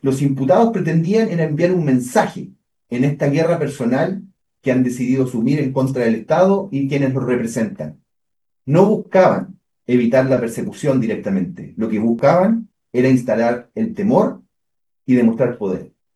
El fiscal regional de La Araucanía, Roberto Garrido, expuso que este asesinato es un mensaje de guerra contra el Estado, por la causa mapuche en la que se sustentan para cometer estos crímenes.